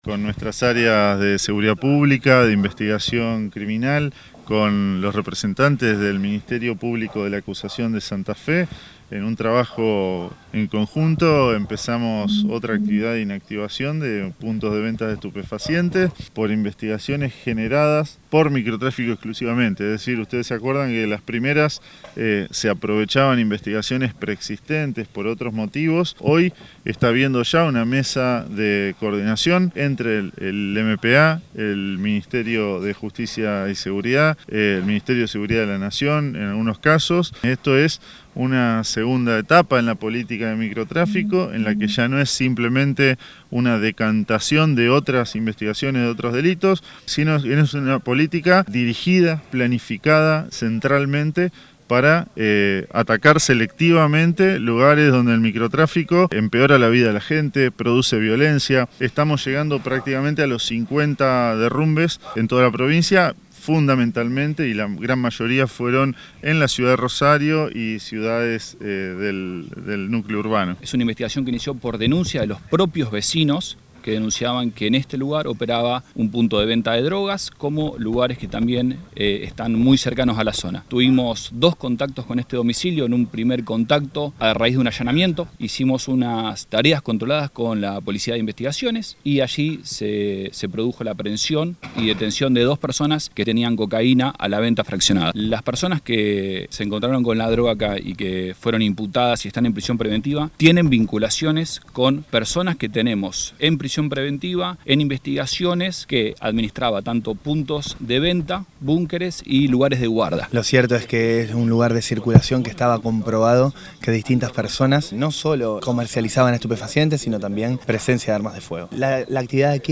Declaraciones de Cococcioni, Pierantoni y Carbone